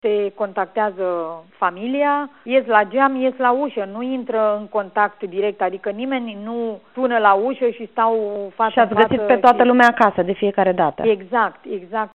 În județul Tulcea, și el cu zero cazuri, autoritățile locale au început să dezinfecteze autobuzele încă de acum o lună, spune subprefectul Carmen Coloianu la Europa FM.